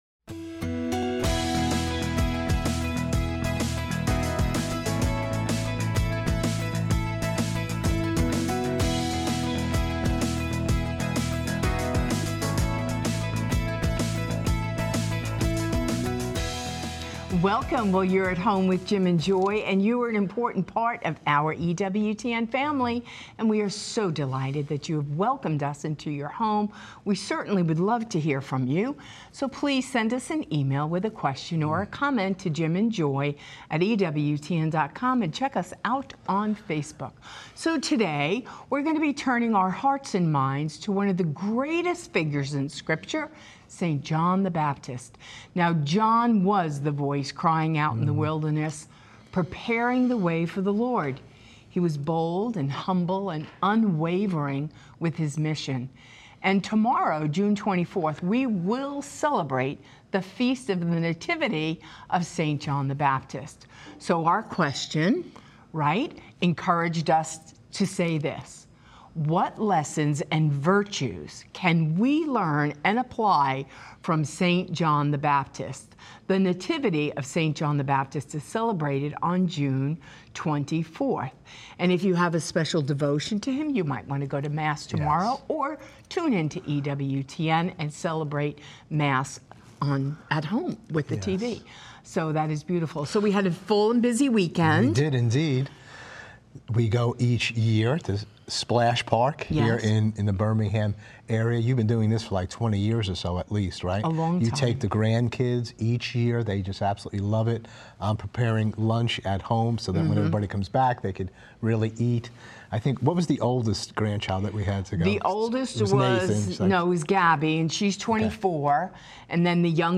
Call-in Show